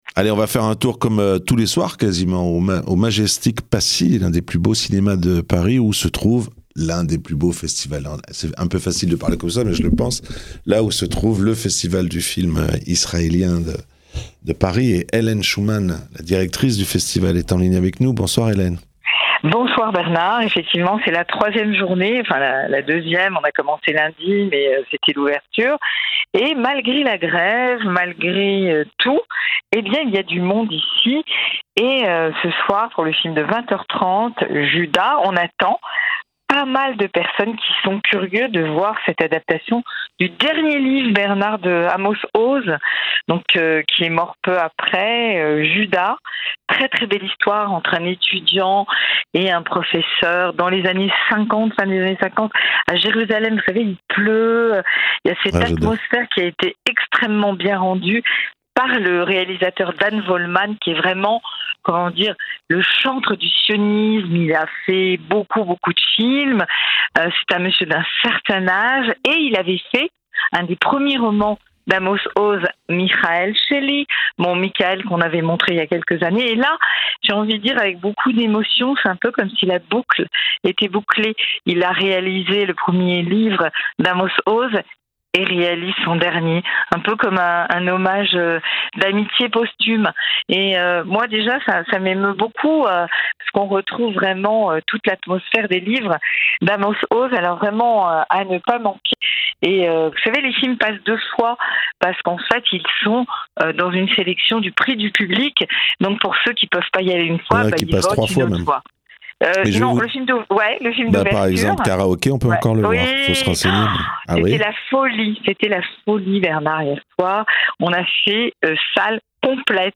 En direct du festival du film israélien, au Majestic Passy